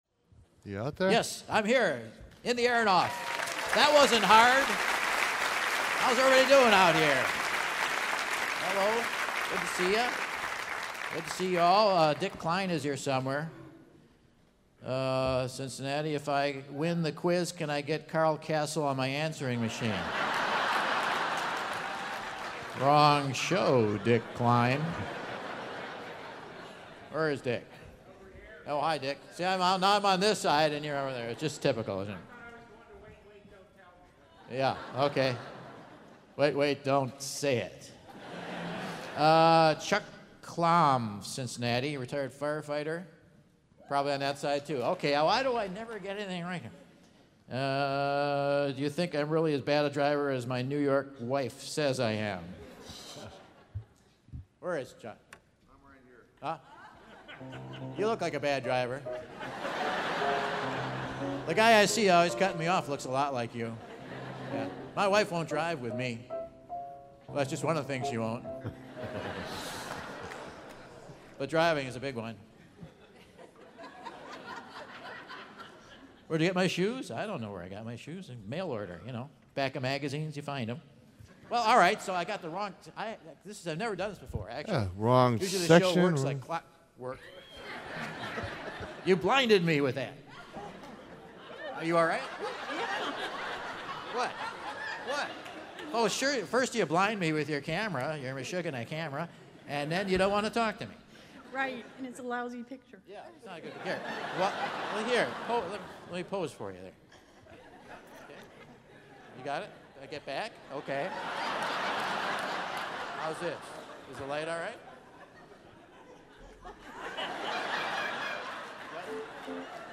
Michael steers a couple of folks through another round of the Whad'Ya Know? Quiz... WYK Quiz in Cincinnati!